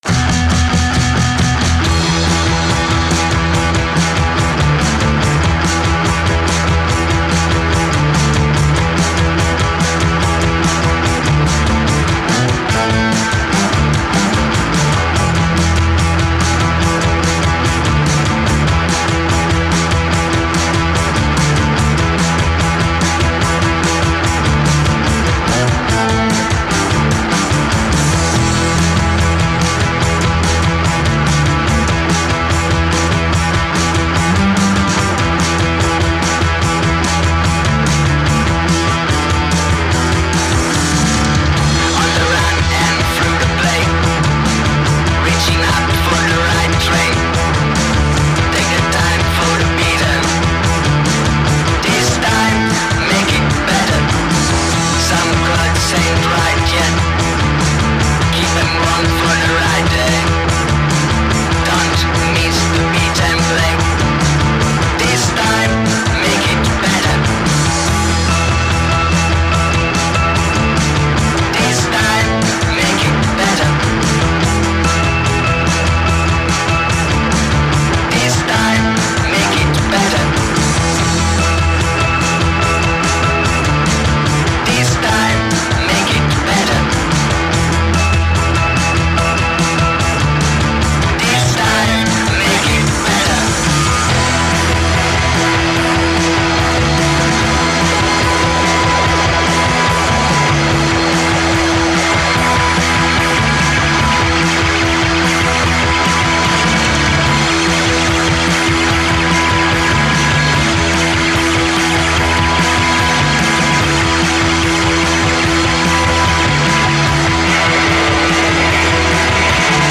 Intervista A/lpaca - Puzzle